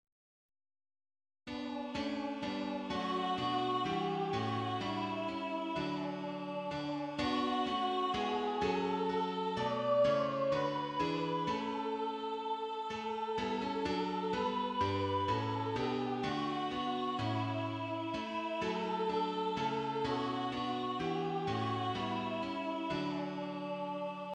HYMN: Olive W Spannaus ©